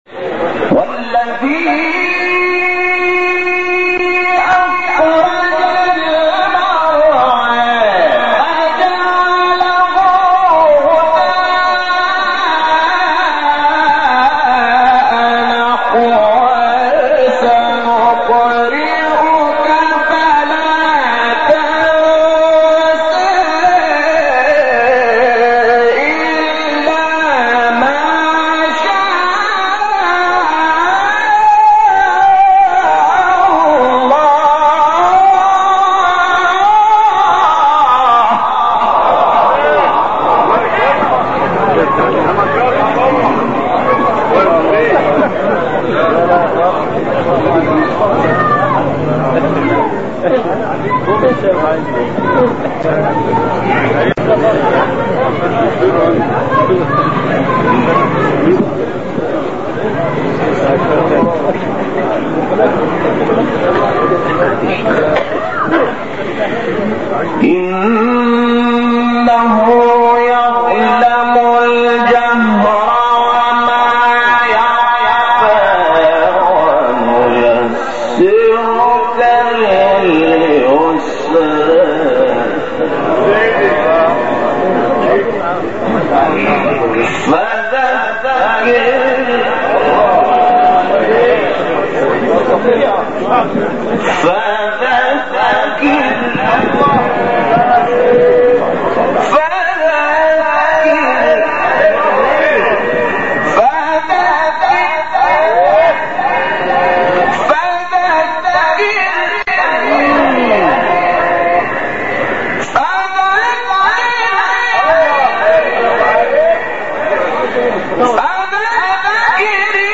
سوره: اعلی آیه: 4-16 استاد: محمود شحات مقام: حجاز قبلی بعدی